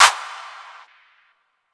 Tm8_Clap40.wav